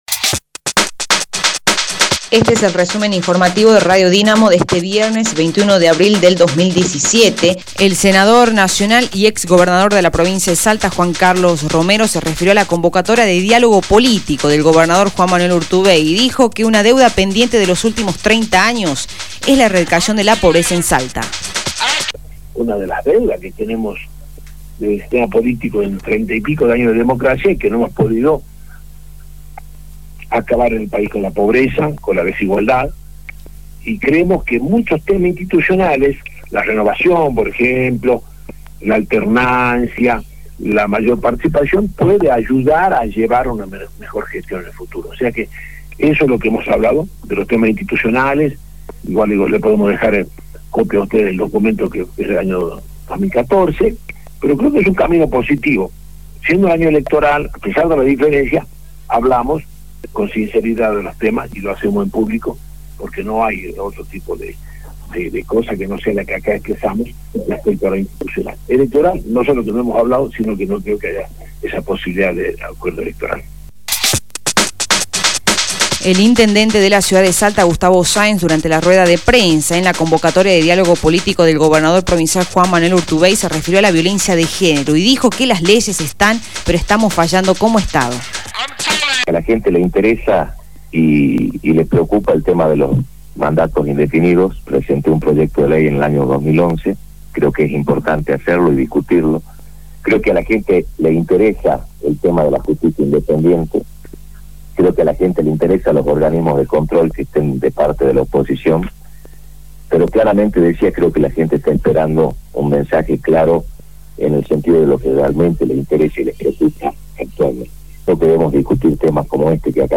El Intendente de la ciudad de Salta, Gustavo Sáenz durante la rueda de prensa en la convocatoria de diálogo político del Gobernador Juan Manuel Urtubey se refirió a la violencia de género y dijo que las leyes están pero estamos fallando como Estado.